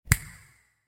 snap.ogg.mp3